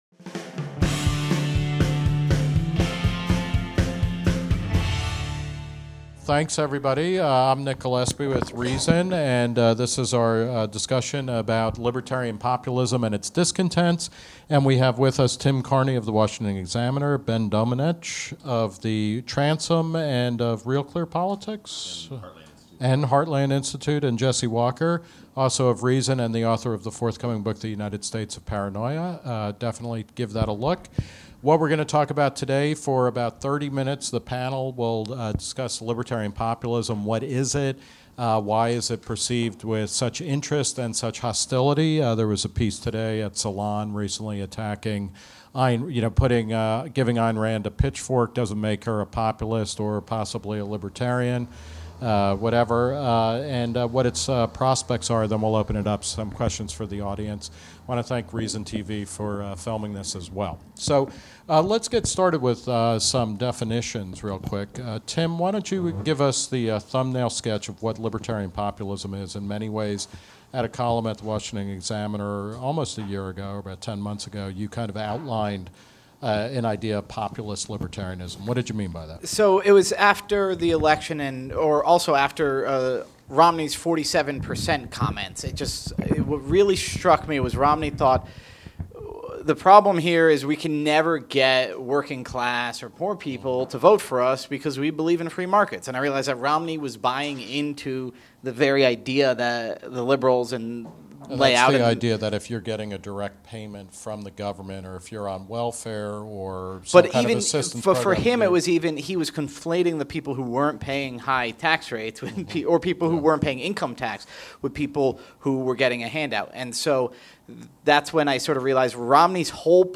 Nick Gillespie and a panel of journalists discuss what it means to be a libertarian populist.